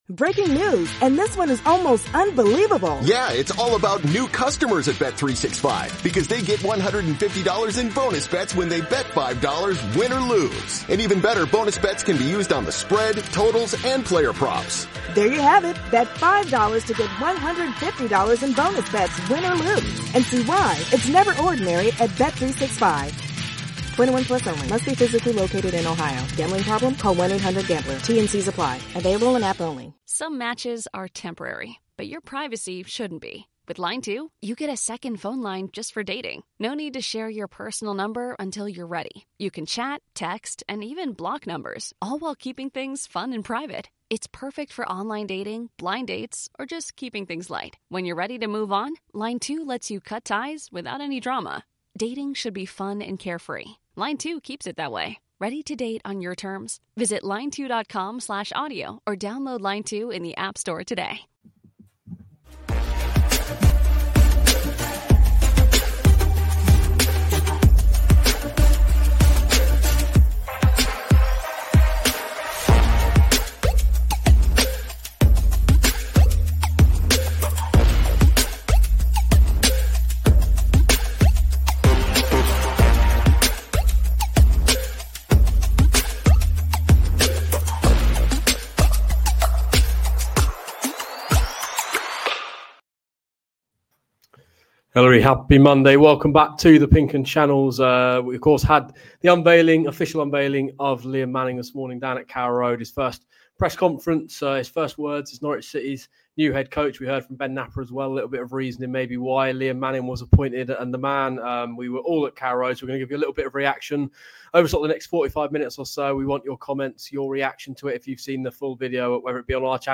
Canaries correspondents